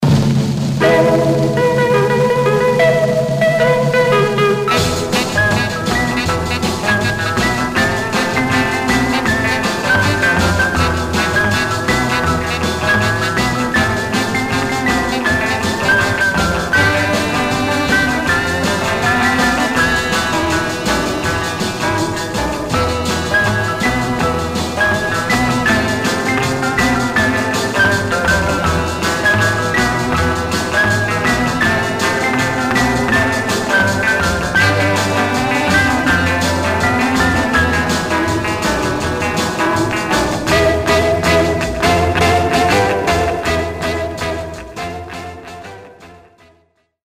Surface noise/wear Stereo/mono Mono
R & R Instrumental